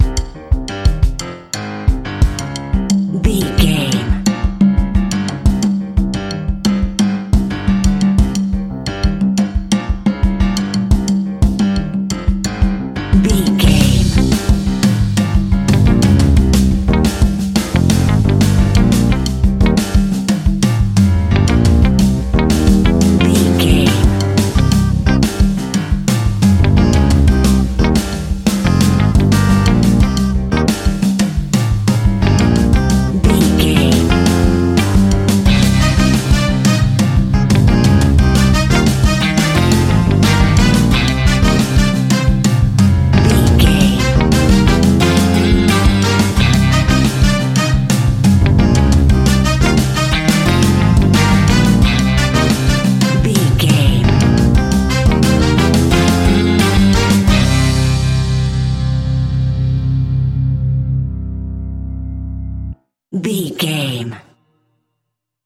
Aeolian/Minor
flamenco
latin
uptempo
drums
bass guitar
percussion
brass
saxophone
trumpet
fender rhodes
clavinet